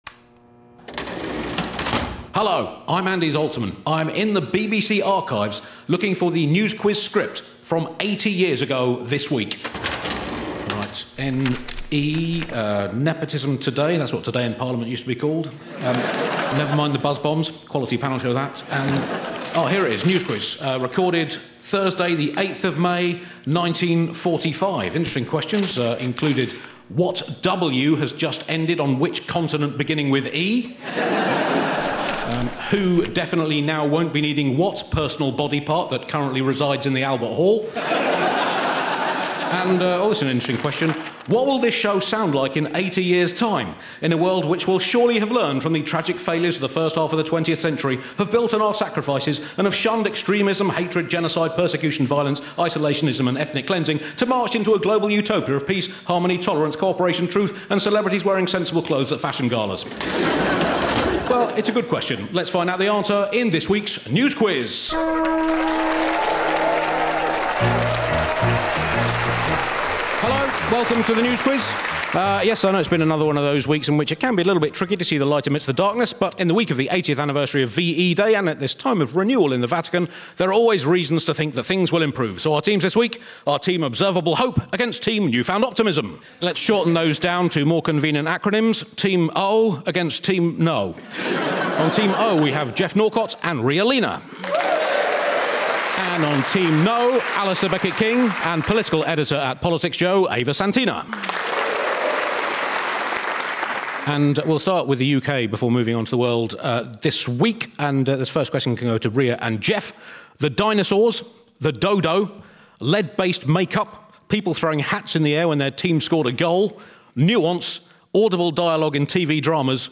Topical panel quiz show, taking its questions from the week's news stories.